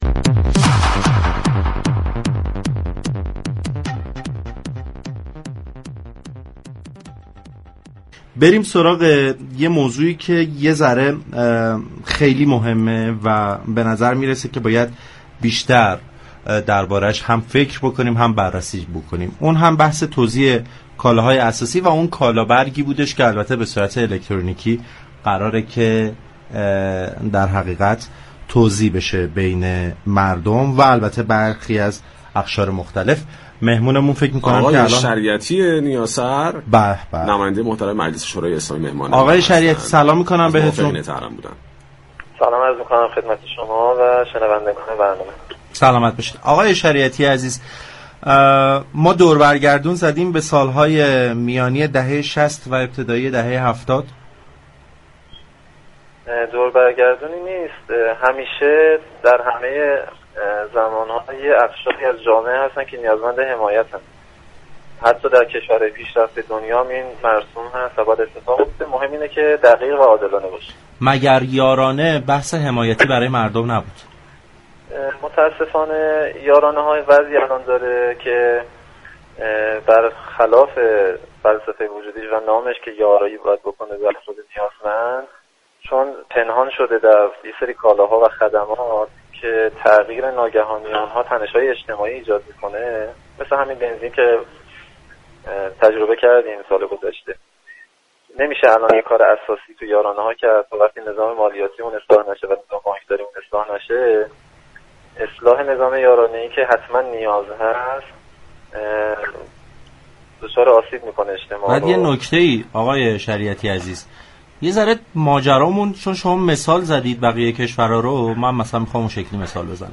مالك شریعتی نیاسر، نماینده مجلس شورای اسلامی در گفت و گو با پارك شهر رادیو تهران ارائه طرح یك فوریتی در صحن مجلس از توزیع كالابرگ های الكترونیكی خبر داد.